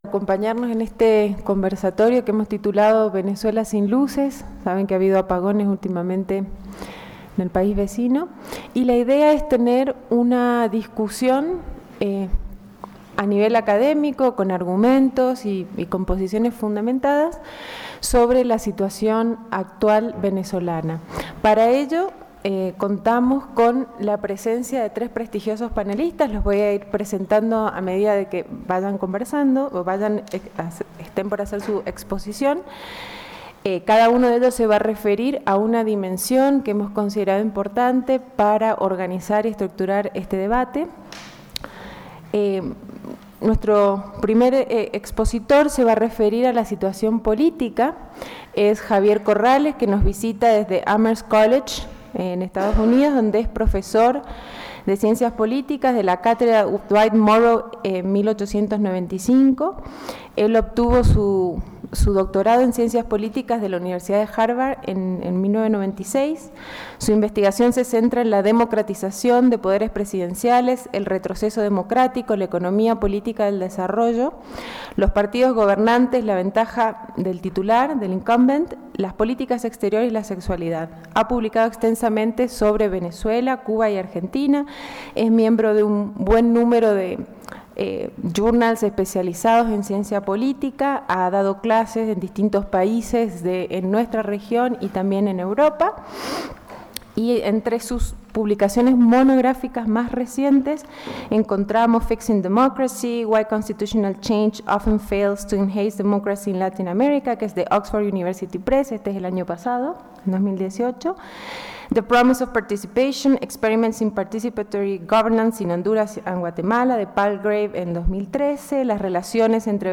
Conversatorio 'Venezuela sin luces. Una discusión sobre la situación actual venezolana'